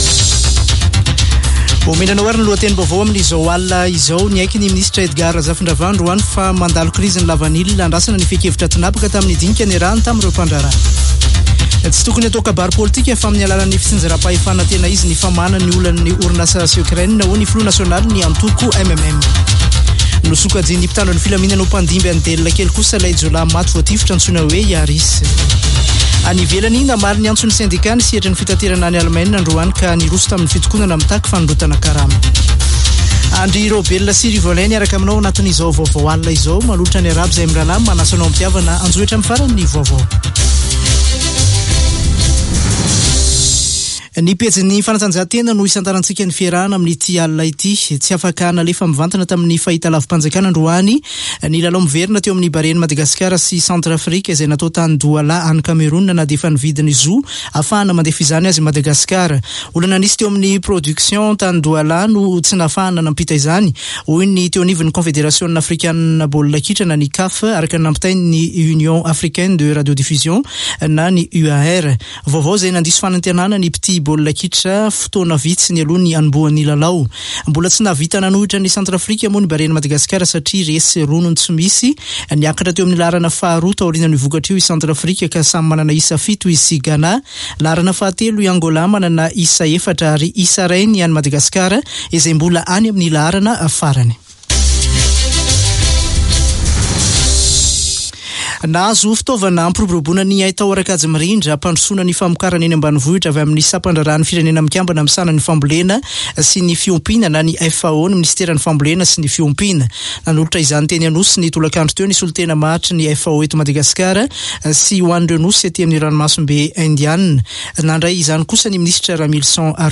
[Vaovao hariva] Alatsinainy 27 marsa 2023